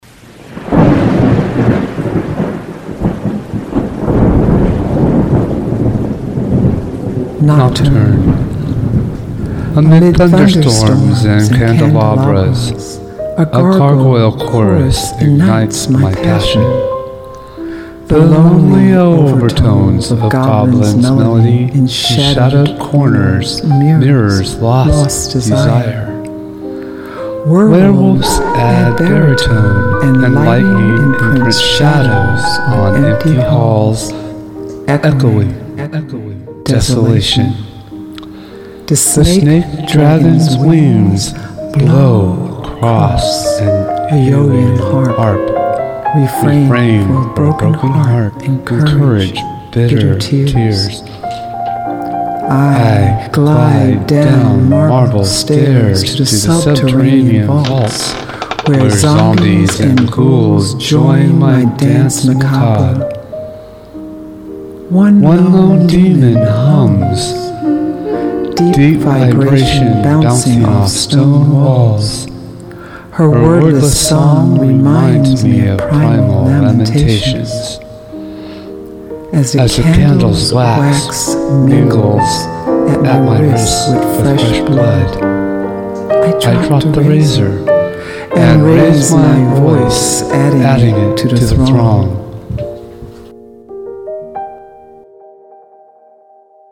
2012 Halloween Poetry Reading
“Nocturne” is from their collaborative poetry collection Dark Duet, forthcoming soon from Necon E-Books and read by the authors.